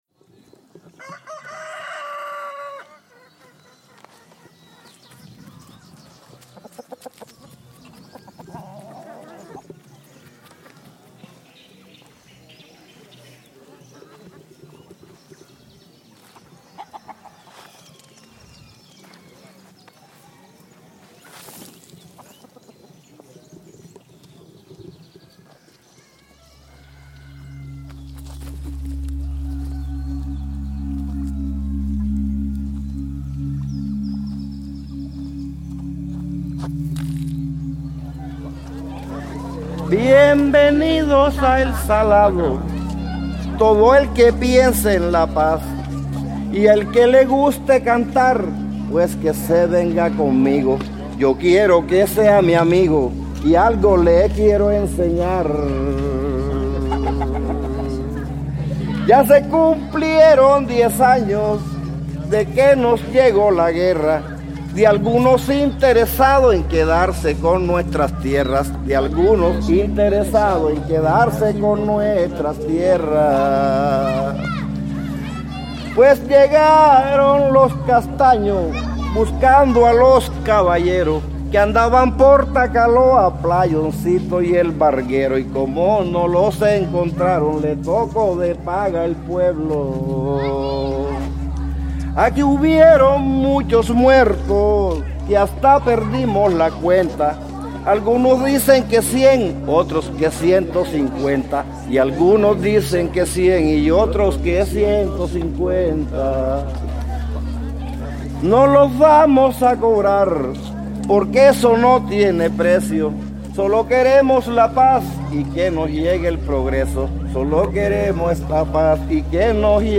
Memoria oral
Un recorrido por la memoria que evoca el dolor, la resistencia, la esperanza y las reivindicaciones de las víctimas que ahora alzan su voz frente a la historia que las silenció. Su memoria se narra a partir de sonidos propios del folclore montemariano, como el vallenato y las décimas, y también desde la poesía y los testimonios que interpretan los relatos de los victimarios.